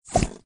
GUI_stickerbook_delete.ogg